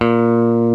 FENDER STRAT 2.wav